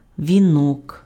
The Ukrainian wreath (Ukrainian: вінок, romanizedvinok, pronounced [ʋʲiˈnɔk]